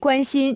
关心 guānxīn 関心を持つ